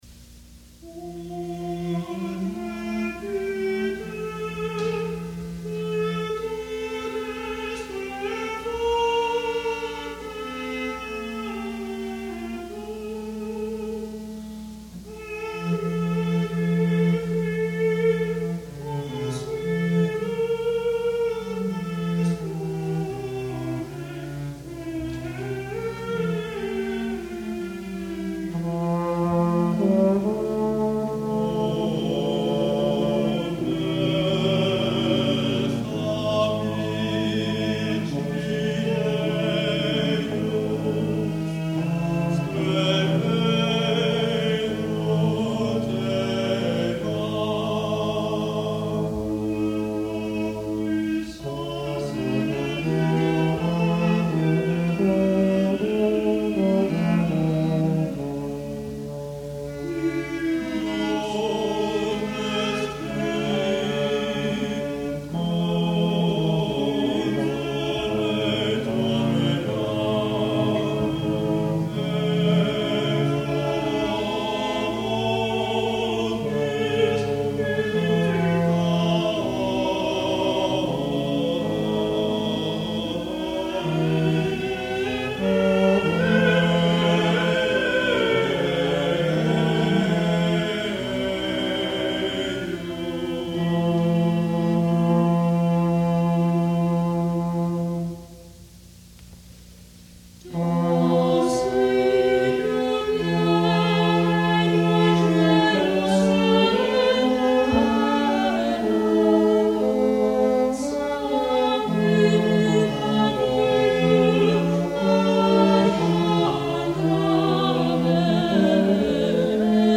Cantus firmus